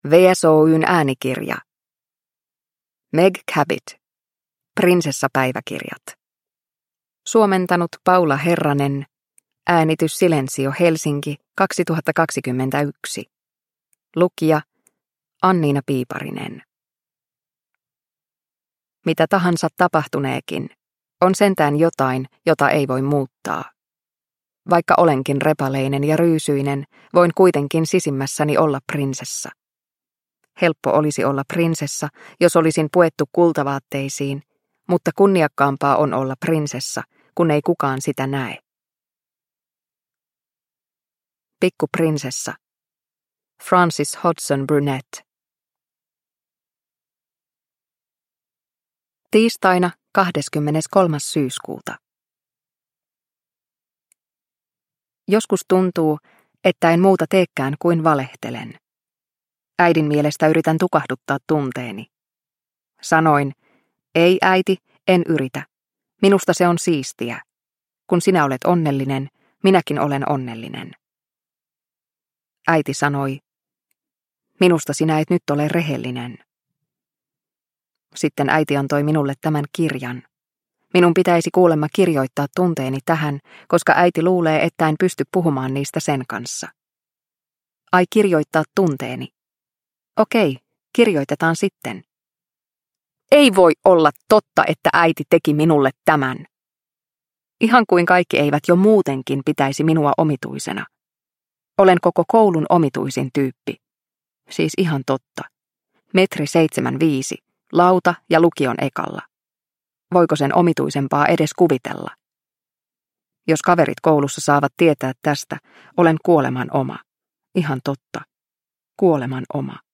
Prinsessapäiväkirjat – Ljudbok – Laddas ner